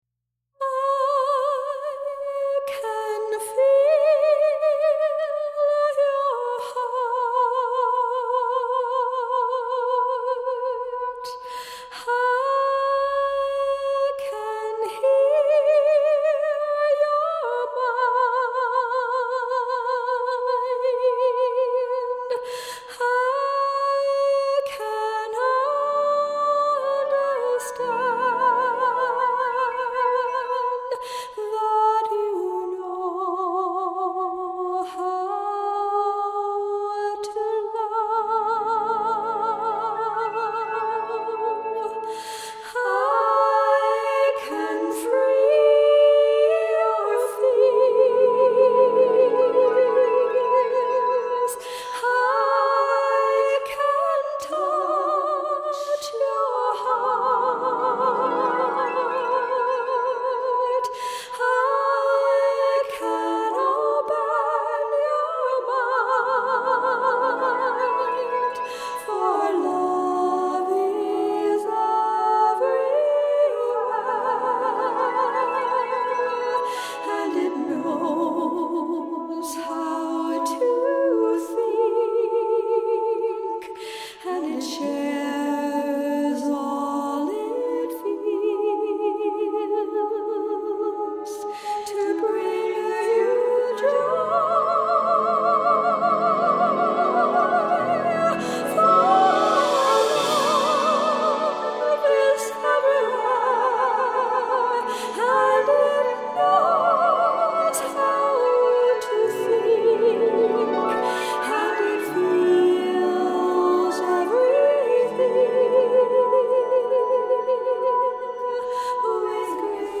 Vocals
Soundscape